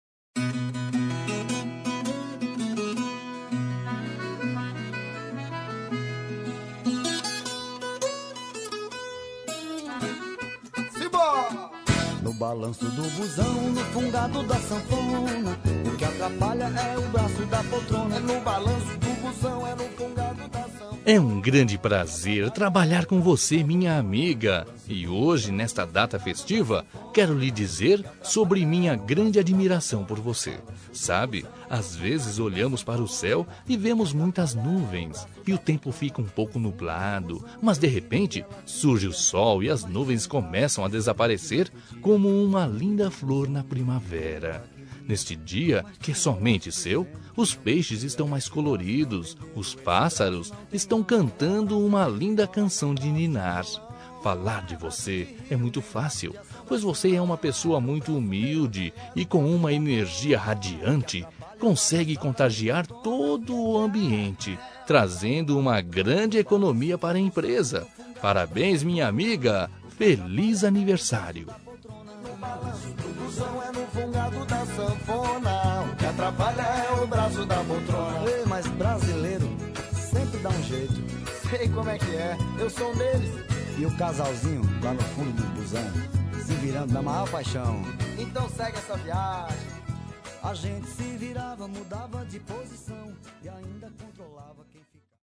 Aniversário de Humor – Voz Masculina- Cód: 200201